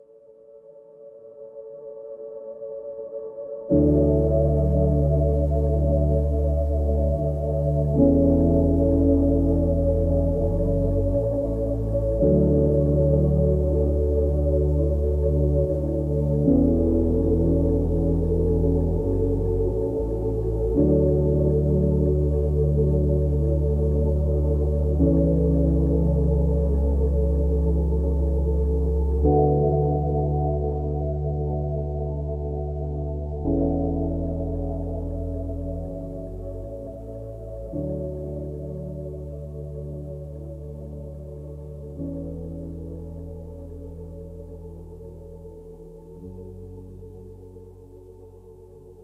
Sound Bath & Frequencies